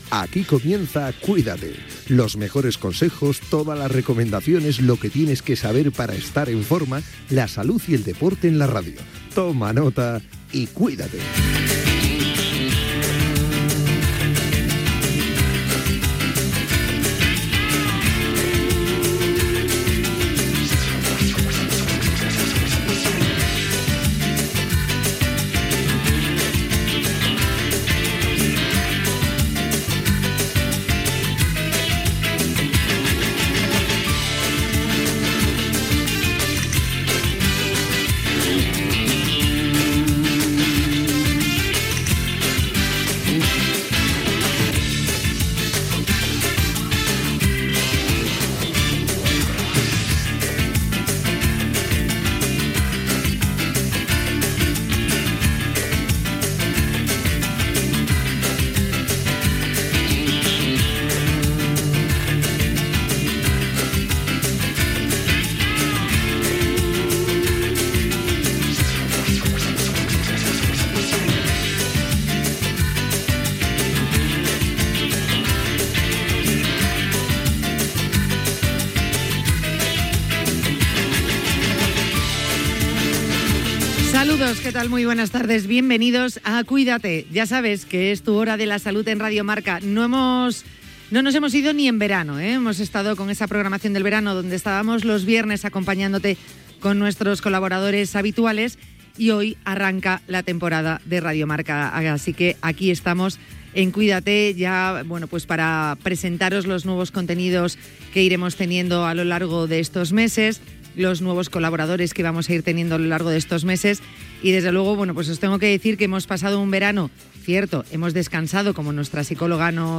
Careta i inici del programa sobre salut i esports. Previsions de seccions per a la temporada 2025-2026.
Divulgació
FM